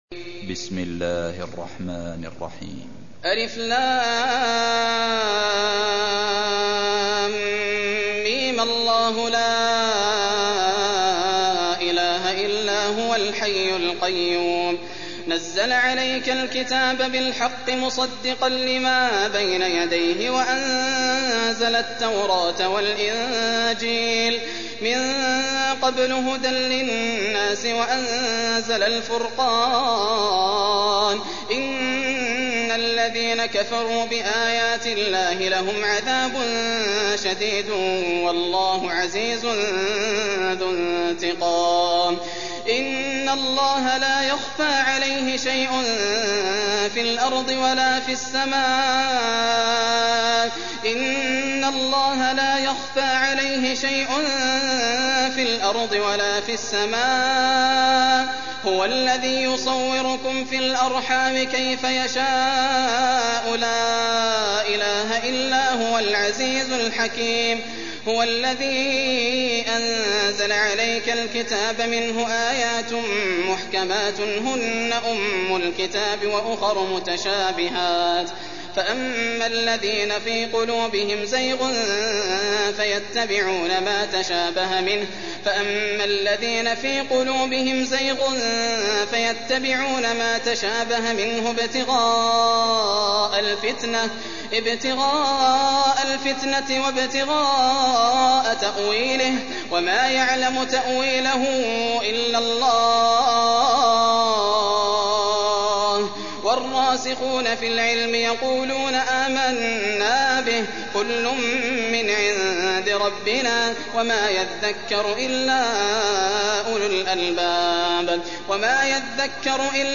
سورة ال عمران 1421 > الإصدارات > المزيد - تلاوات ياسر الدوسري